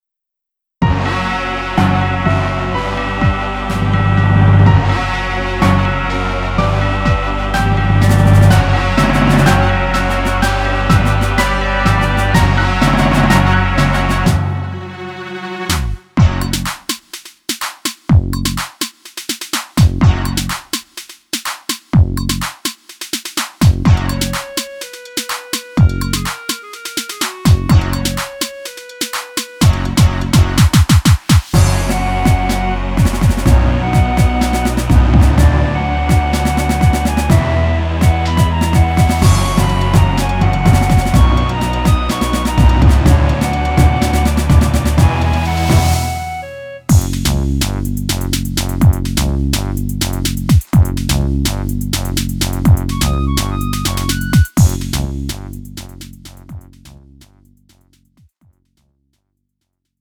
음정 -1키 2:55
장르 가요 구분 Lite MR